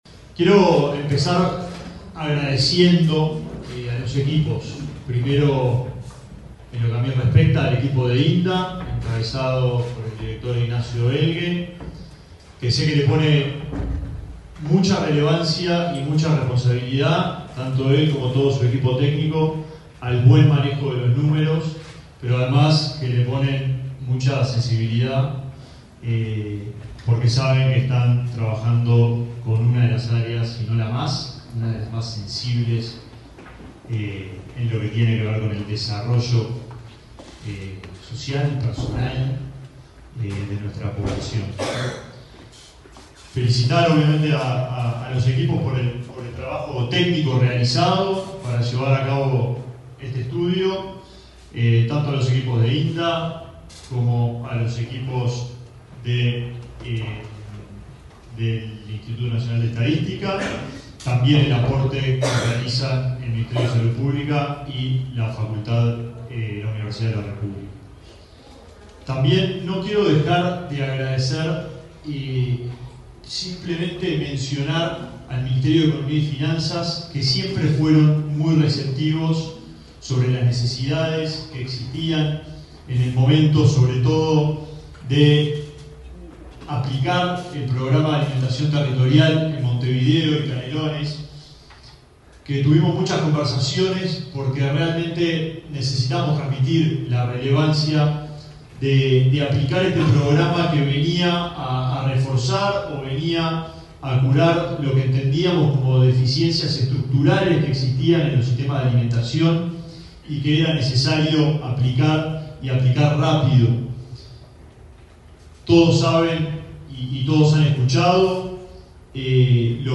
Palabra de autoridades en acto en el Mides